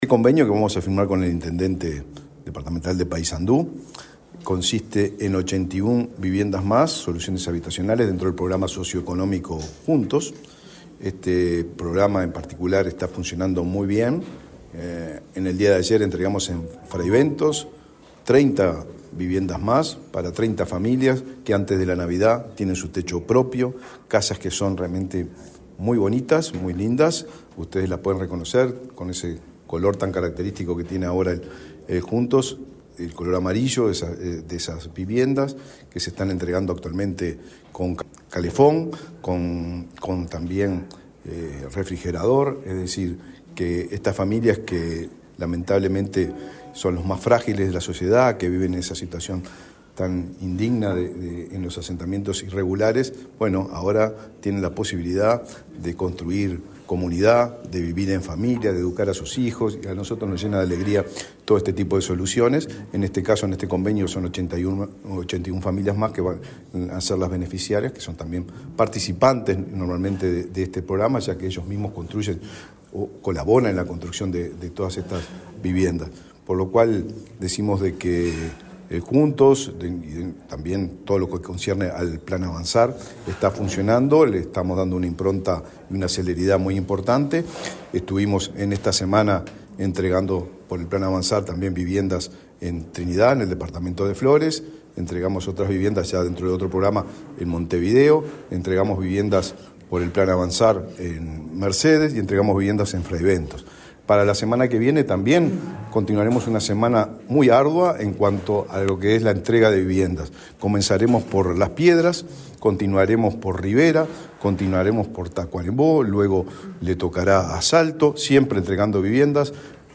Declaraciones del ministro de Vivienda, Raúl Lozano
Declaraciones del ministro de Vivienda, Raúl Lozano 15/12/2023 Compartir Facebook X Copiar enlace WhatsApp LinkedIn El ministro de Vivienda, Raúl Lozano, firmó , este viernes 15 en Montevideo, un convenio con el intendente de Paysandú, Nicolás Olivera, para el realojo de 81 familias de ese departamento. Luego el secretario de Estado dialogó con la prensa.